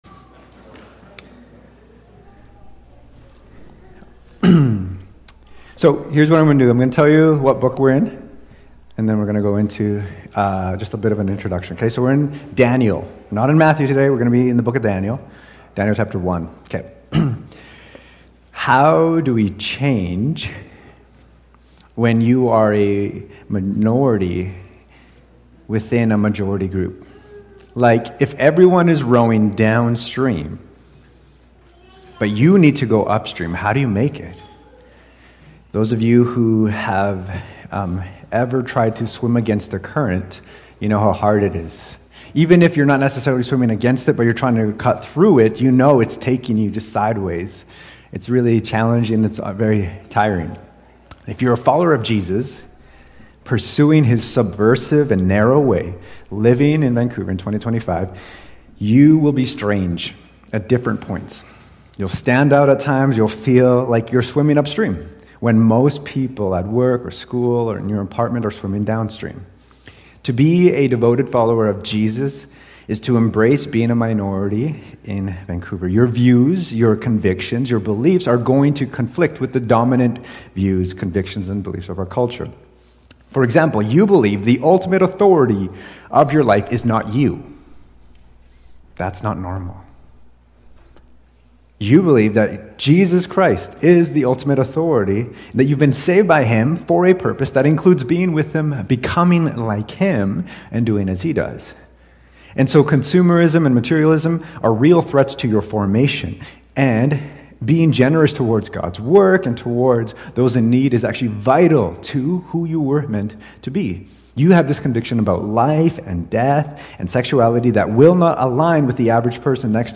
Cascades Church Sermons